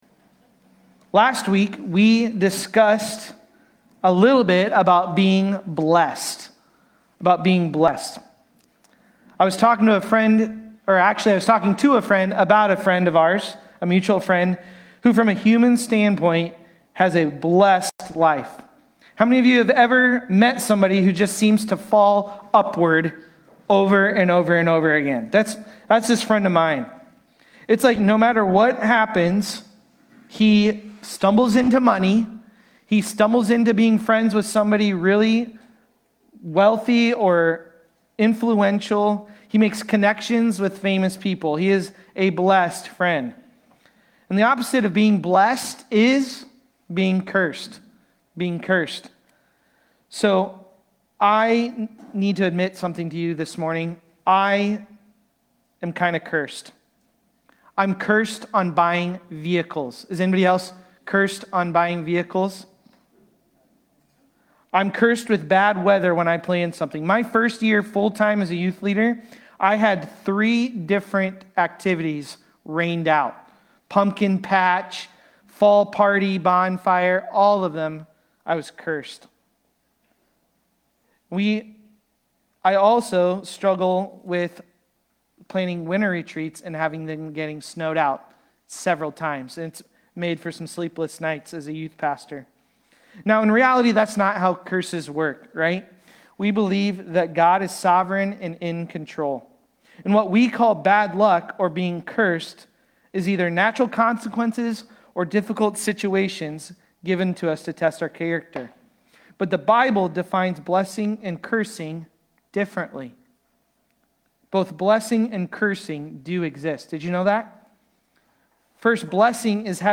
Galatians-3.10-14-Sermon-Audio.mp3